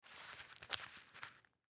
Звуки кошелька
Звук берем кошелек в руку